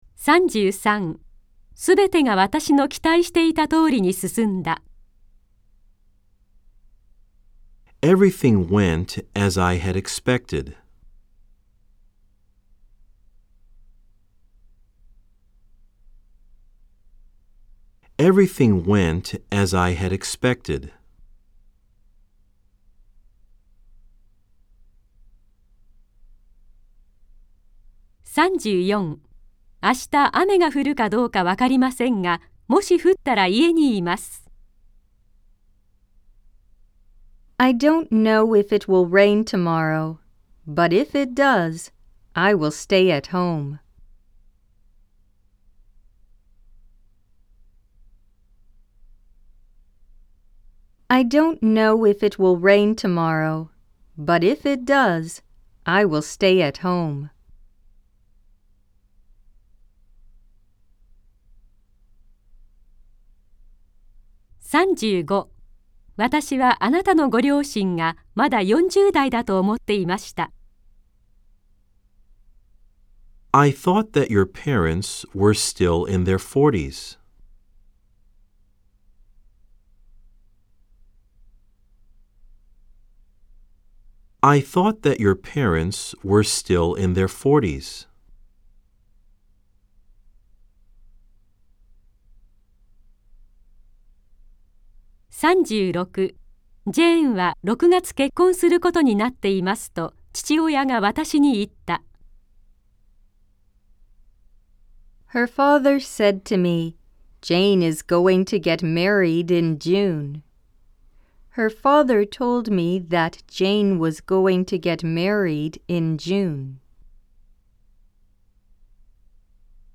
（4）暗唱例文100　各章別ファイル（日本文＋英文2回読み）
※（1）（2）では英文のあとに各5秒のポーズ、（3）（4）では各7秒のポーズが入っています。